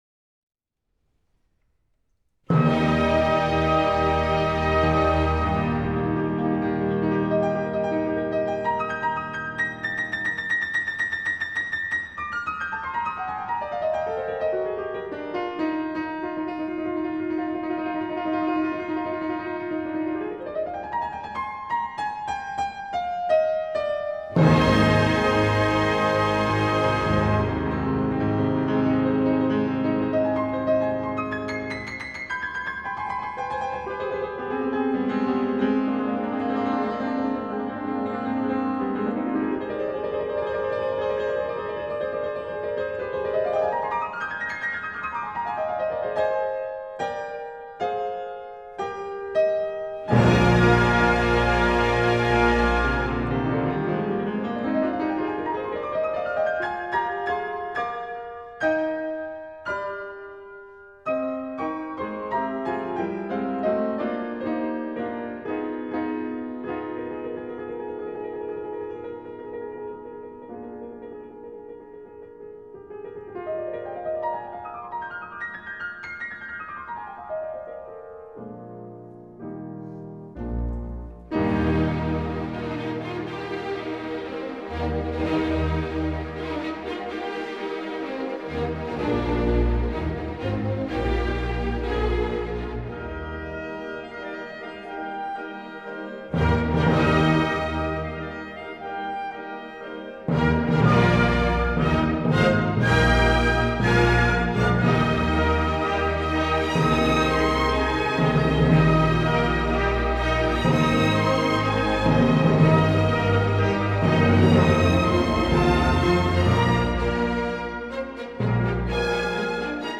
First Movement: Allegro. Staatskapelle Dresden, Sir Colin Davis, Conductor, Claudio Arrau, Pianist.
01---beethoven_-piano-concerto-no.5-in-e-flat-major-op.73--.mp3